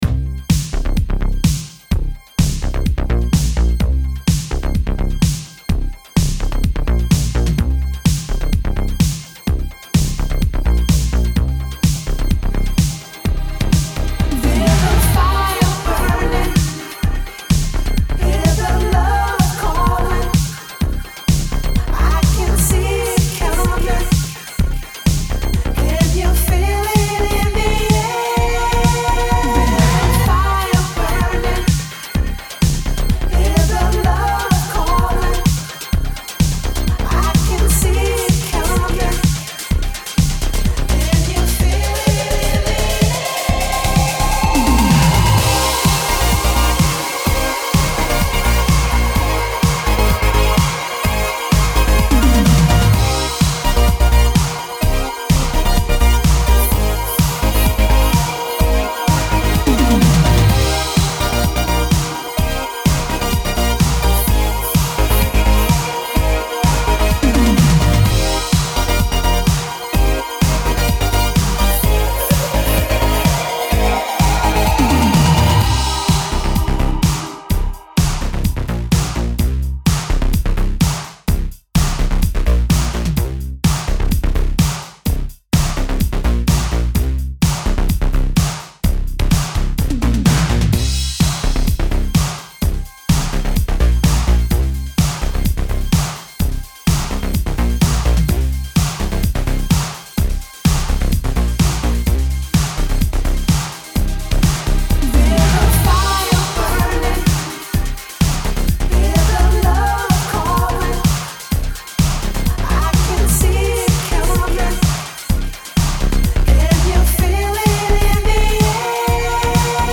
Last week we had an exclusive mix Canadian duo Woodhands!